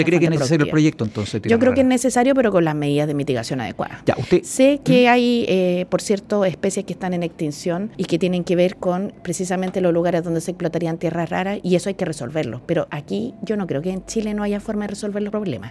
En conversación con Radio Bío Bío en Concepción, afirmó que ve al proyecto con buena perspectiva de explotación minera y que se debe buscar la forma para no afectar a las especies que habitan en el lugar donde se pretende emplazar.